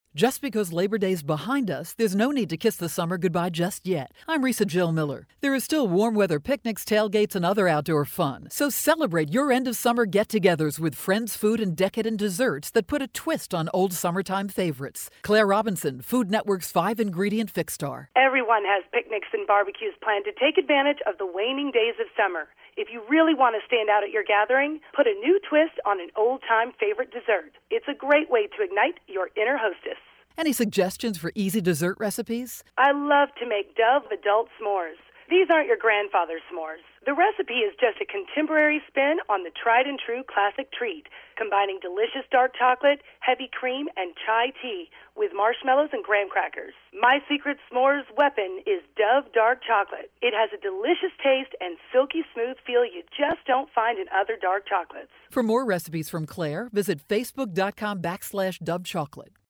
September 26, 2012Posted in: Audio News Release